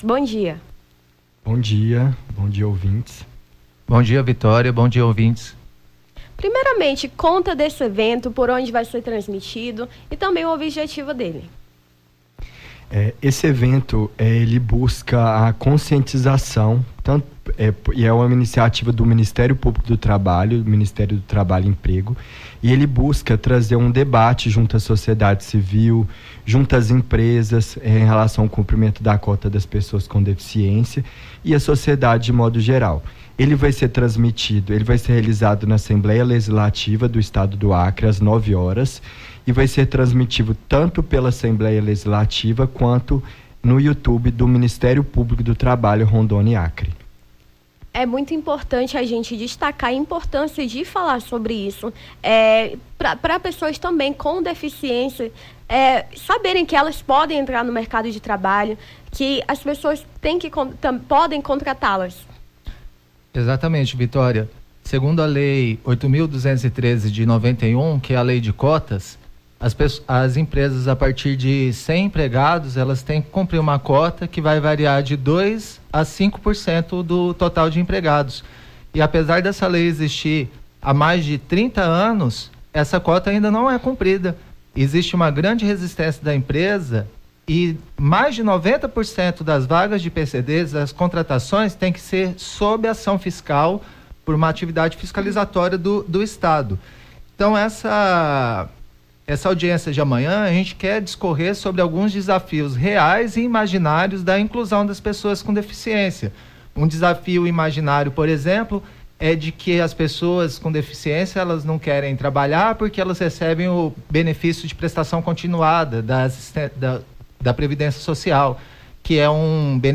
Nome do Artista - CENSURA - ENTREVISTA (MPT AUDIENCIA PUBLICA) 12-07-23.mp3